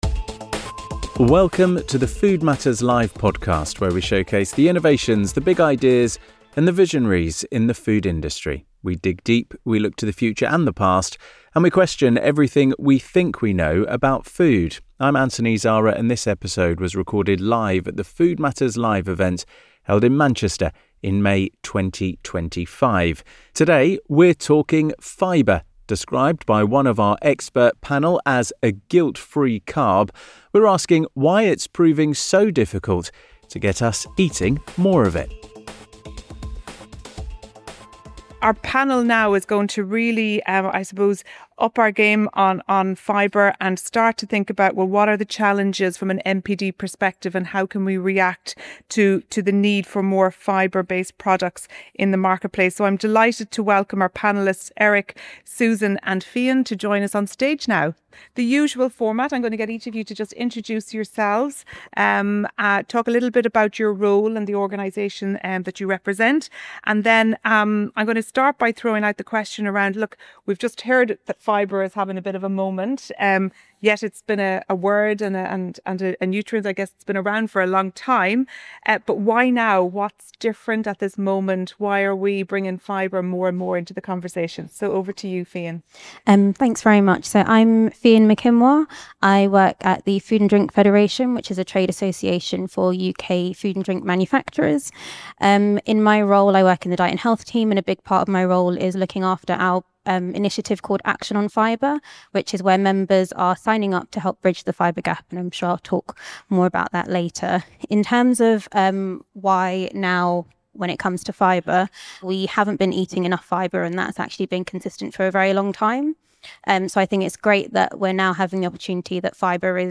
In this episode of the Food Matters Live podcast, recorded live at our event in Manchester in May 2025, our expert panel explores why fibre could finally be having its moment.